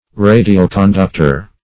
Search Result for " radioconductor" : The Collaborative International Dictionary of English v.0.48: Radioconductor \Ra`di*o*con*duc"tor\ (r[=a]`d[i^]*[-o]*k[o^]n*d[u^]k"t[~e]r), n. (Elec.)